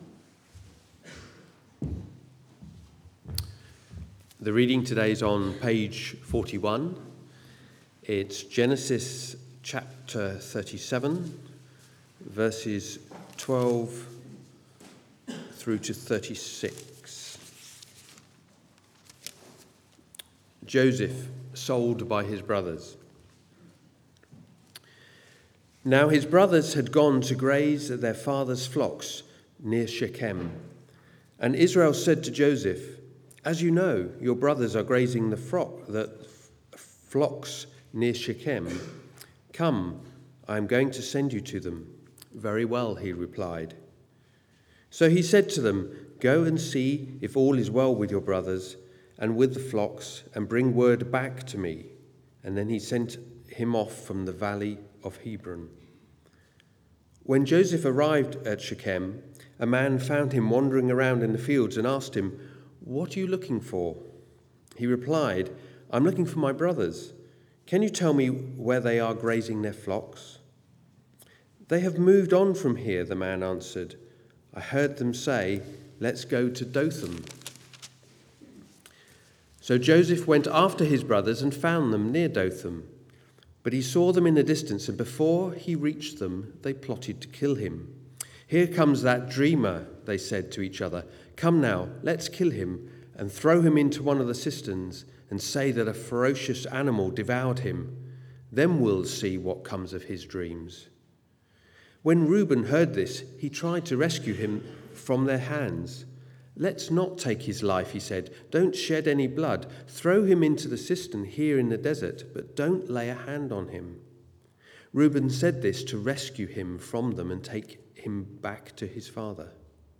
Arborfield Morning Service
Joseph and the blood-spattered robe Sermon